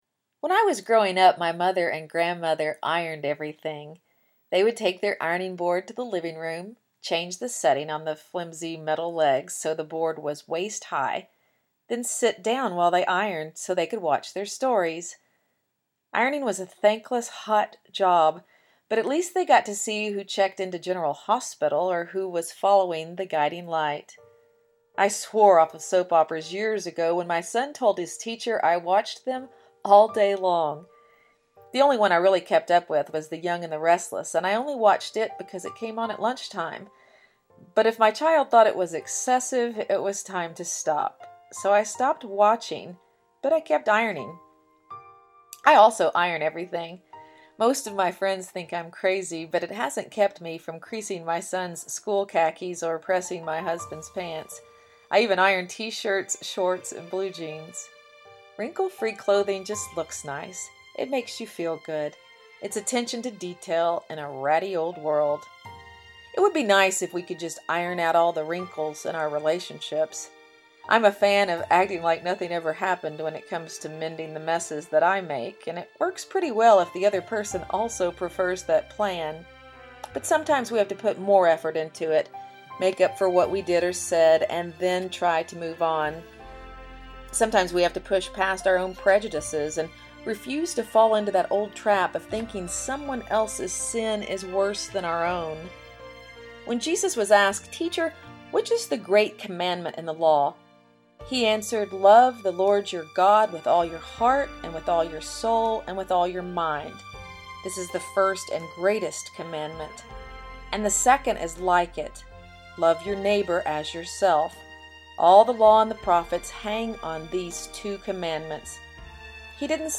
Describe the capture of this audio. Audio and Music version